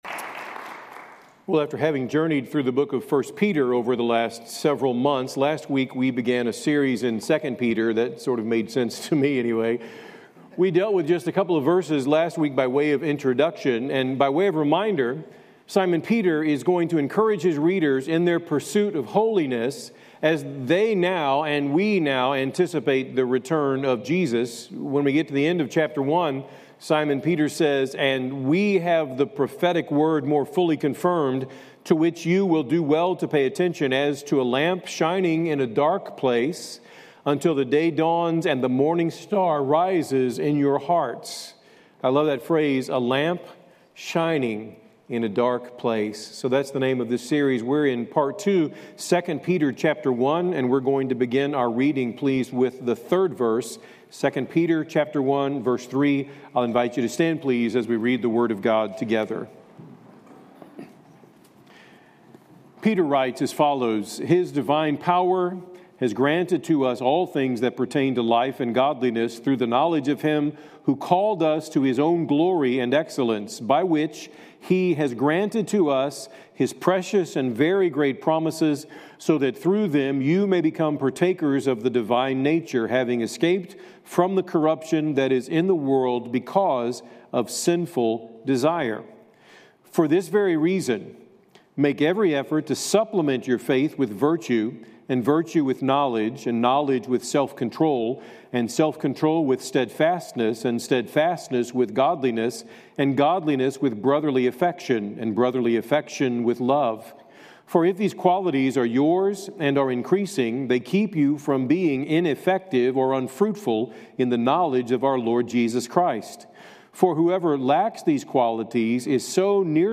First Baptist Church Kearney MO - Sermon, A Lamp Shining in a Dark Place (Part 10), June 29, 2025